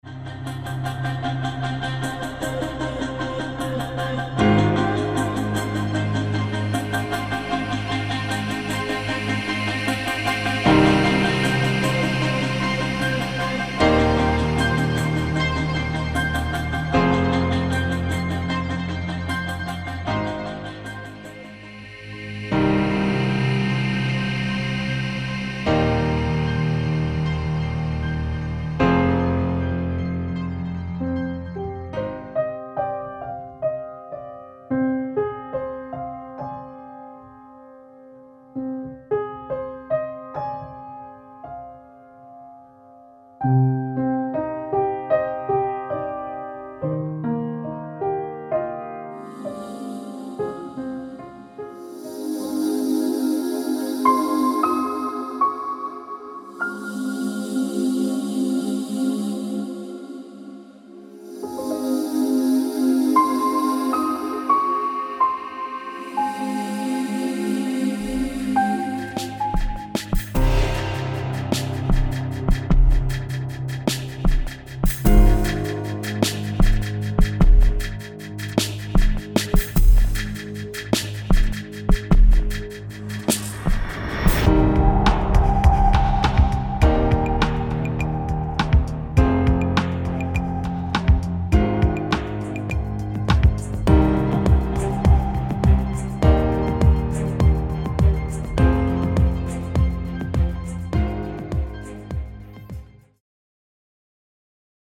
Alt. 1:  A sparser & shorter version of the Main theme.